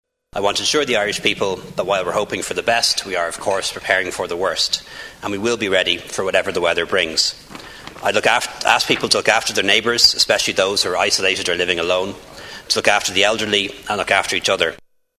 The Taoiseach Leo Varadkar says the country is prepared for the storm to come: